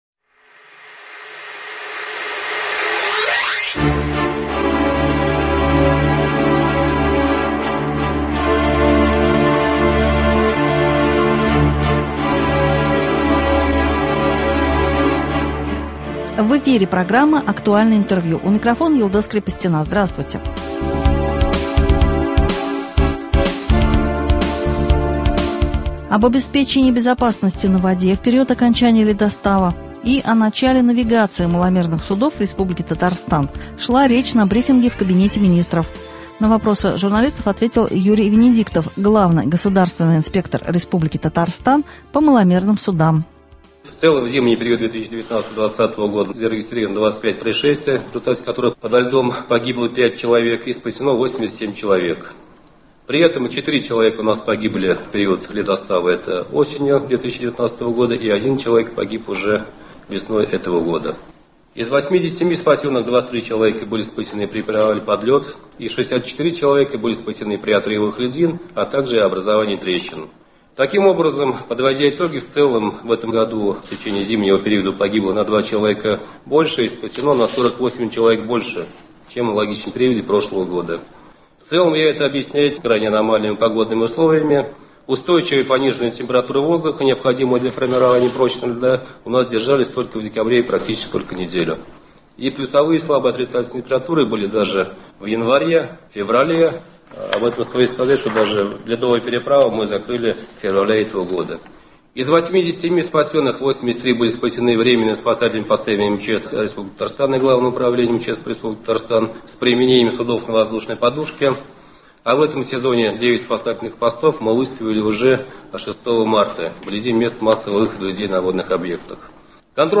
«Актуальное интервью». 1 апреля.
Об обеспечении безопасности на воде в период окончания ледостава и начала навигации маломерных судов в Республике Татарстан шла речь на брифинге в Кабинете министров, спикером выступил Юрий ВЕНЕДИКТОВ — Главный государственный инспектор Республики Татарстан по маломерным судам.